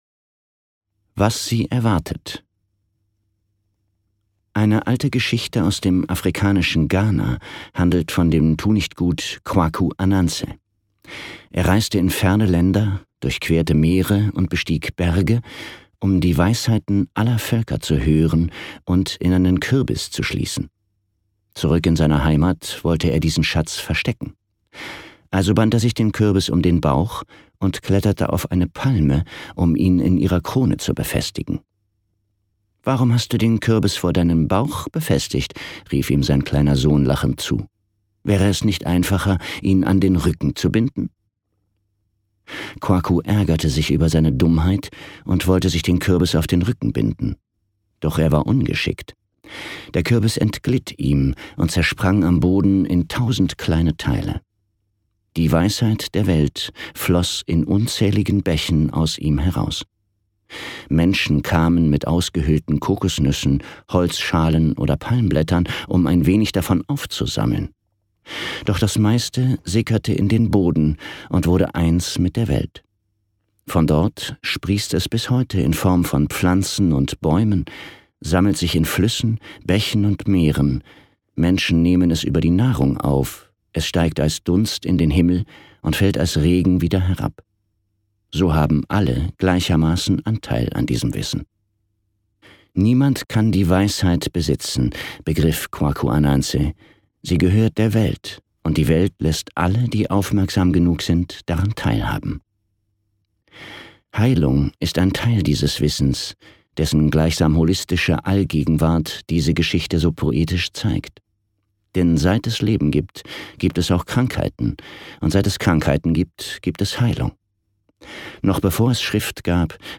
Dieses Hörbuch ist eine universelle Gebrauchsanleitung für Körper, Geist und Seele, die moderner wissenschaftlicher Überprüfung standhält.
Gekürzt Autorisierte, d.h. von Autor:innen und / oder Verlagen freigegebene, bearbeitete Fassung.